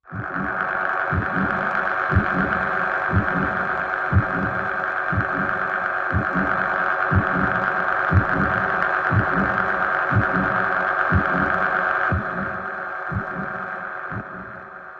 Heart Pump, Machine, Heart Beat, Low Pulse, Electric Motor